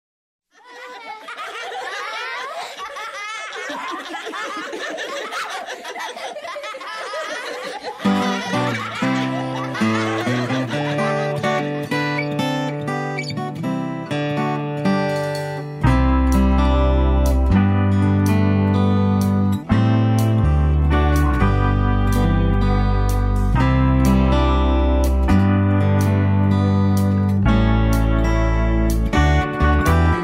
▪ The full instrumental track